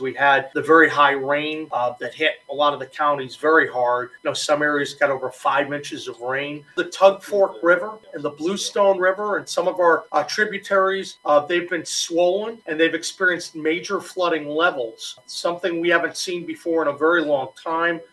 Flooding from heavy rain and snowmelt in southern West Virginia has resulted in Governor Patrick Morrisey declaring a state of emergency for 13 counties.  Morrisey held a press briefing to go over current conditions where power has been out and 38 swiftwater rescues have been conducted.